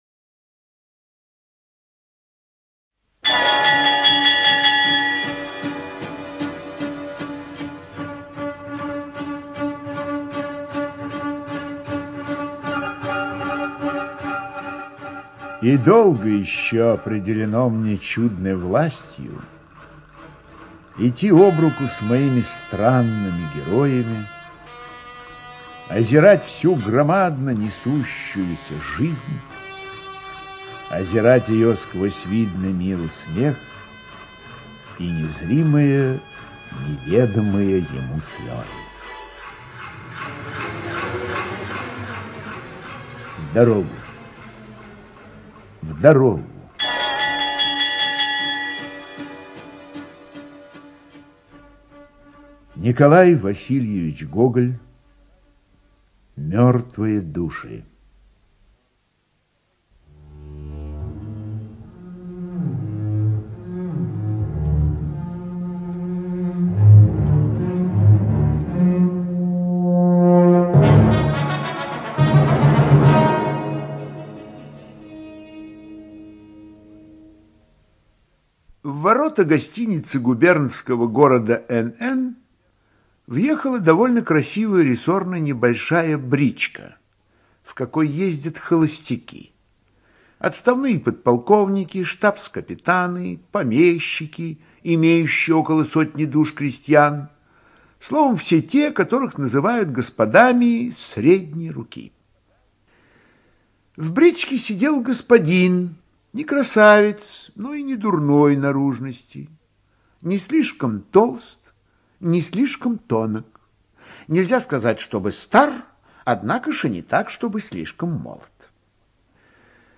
ЧитаетУльянов М.